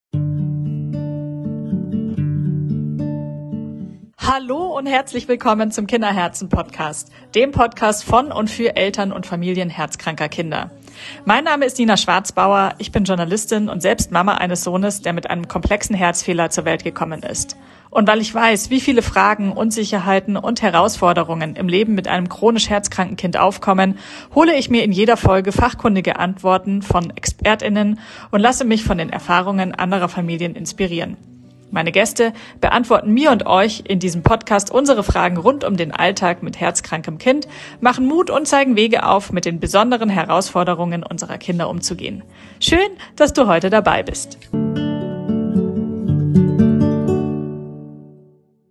Mit einer positiven, bestärkenden und verständnisvollen Tonalität sprechen wir die Hörer dort an, wo sie gerade stehen.
Trailer Sie sind neugierig geworden?